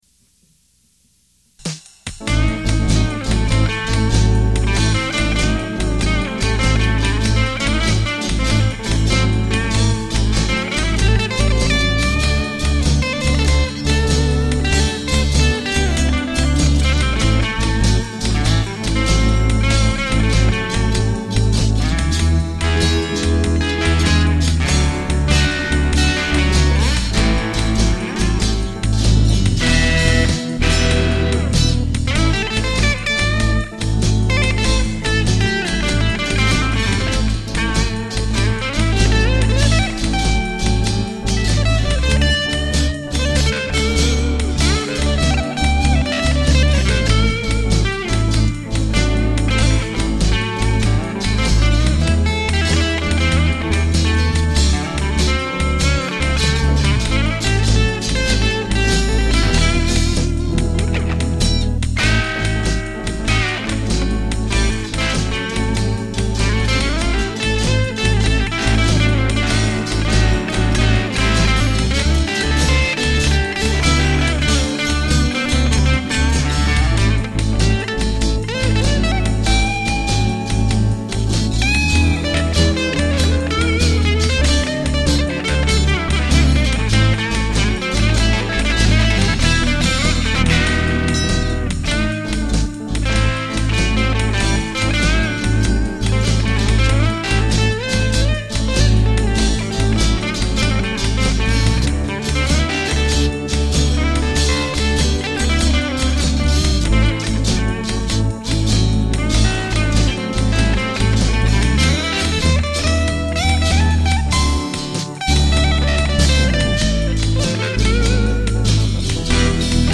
dance/electronic
Blues